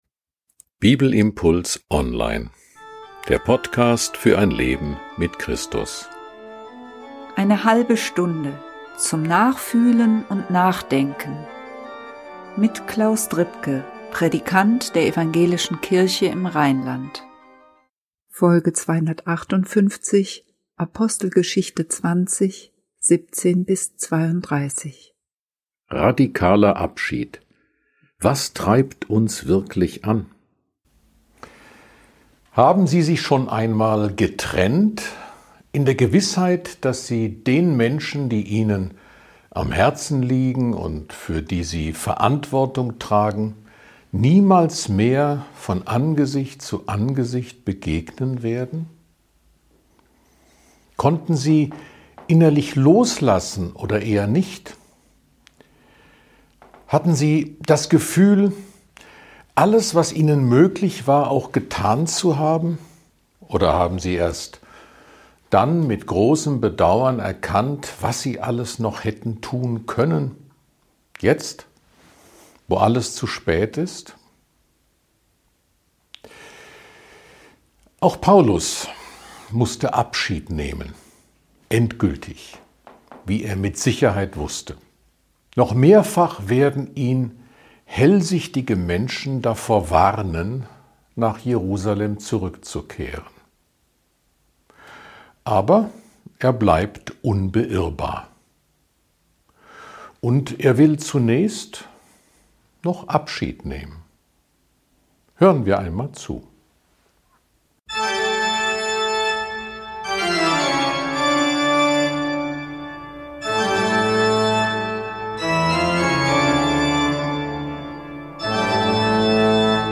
Ein Bibelimpuls zu Apostelgeschichte 20, 17-32.